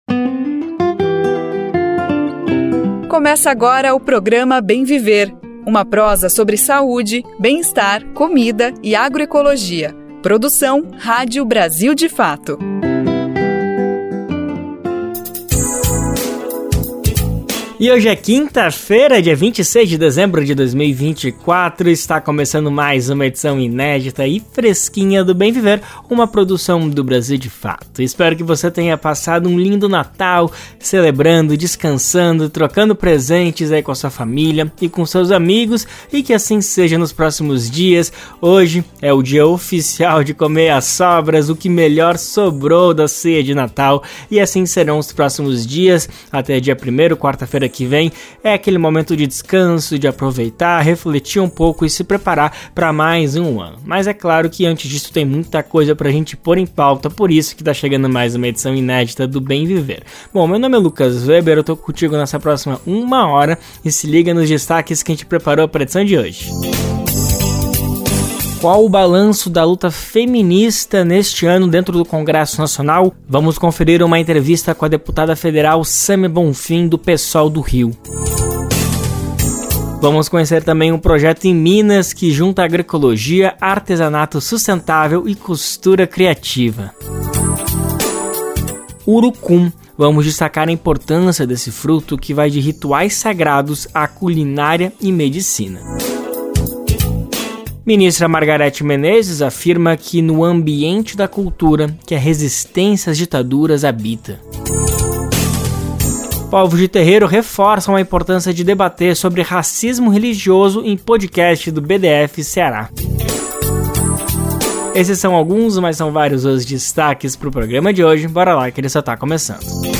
Nesta quinta-feira (26), o programa Bem Viver, produzido pelo Brasil de Fato, apresenta destaques que refletem as lutas e conquistas do ano, com entrevistas e reportagens que abordam temas como feminismo, agroecologia, sustentabilidade, cultura e a luta contra o racismo religioso.